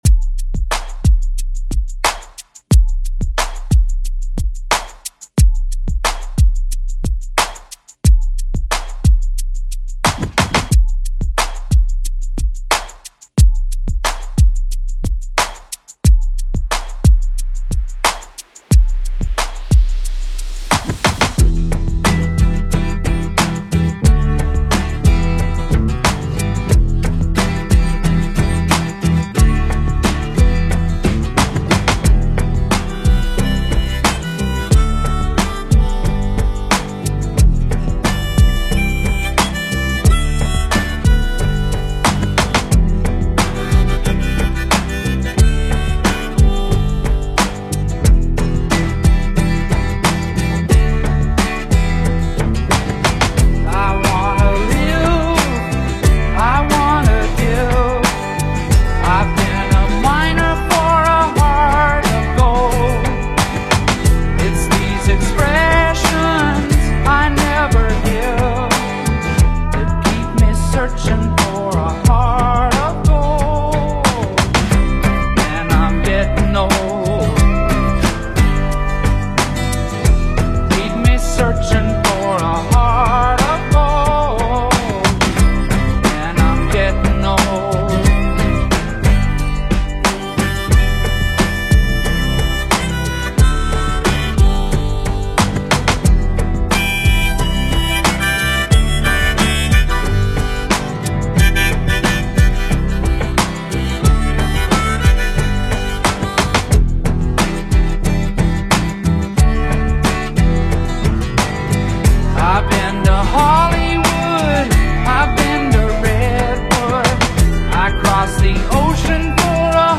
BPM: 90 Time